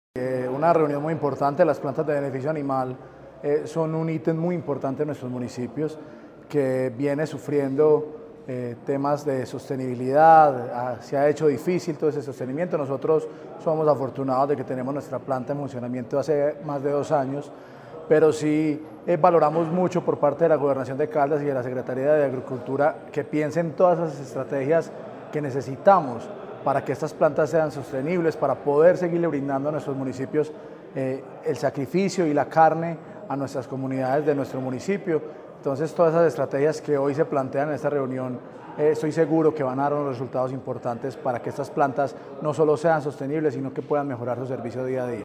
Juan Camilo Isaza Gonzáles, alcalde de Pácora.